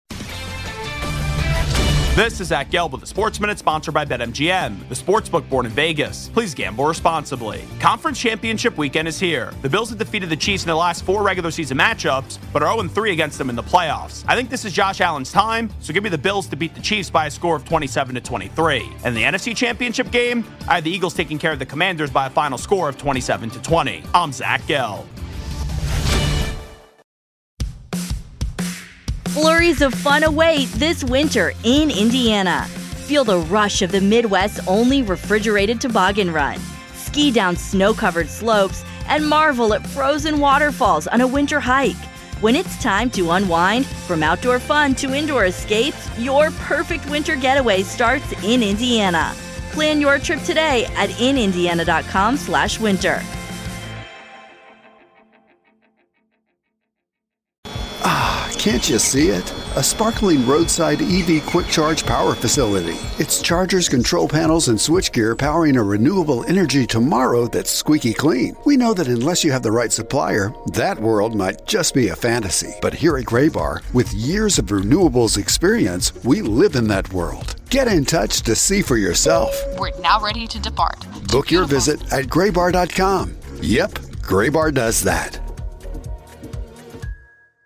Hourly Commentaries between 6am-7pm by Infinity Sports Network talent